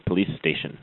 police.mp3